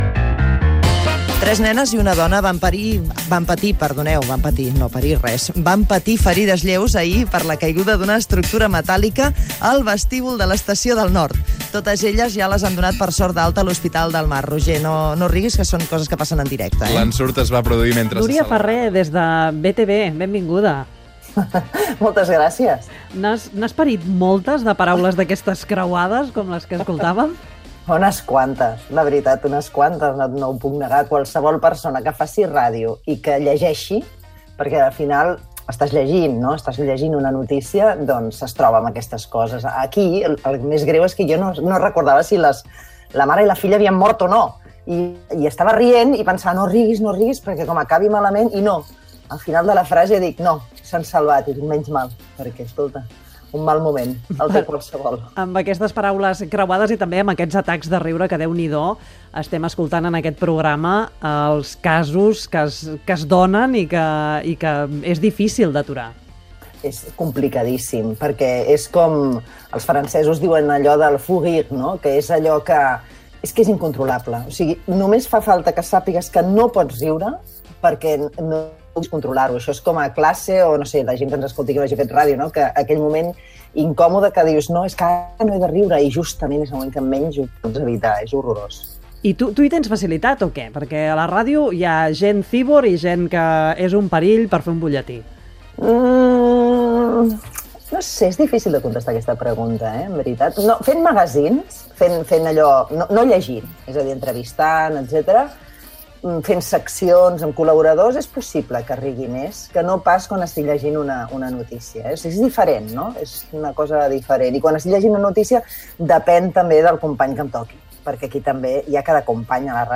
Programa emès amb motiu del Dia Mundial de la Ràdio de 2022.
FM